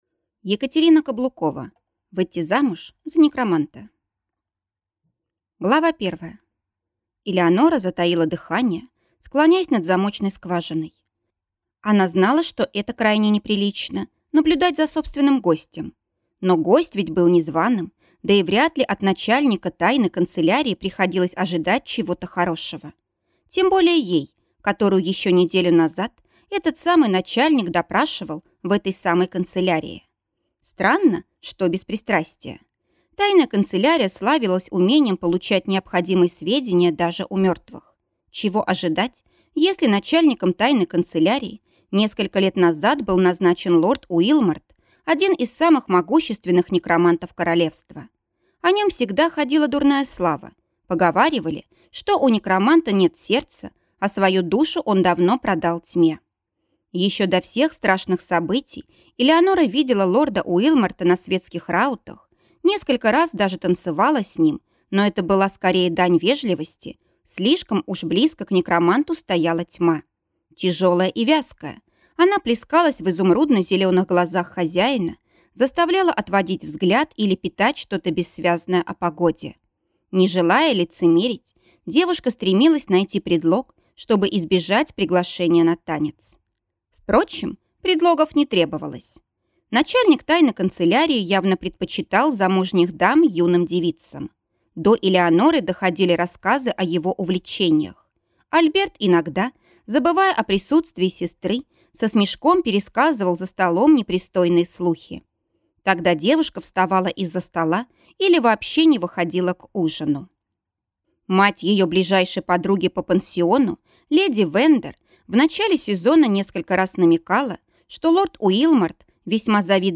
Аудиокнига Выйти замуж за некроманта | Библиотека аудиокниг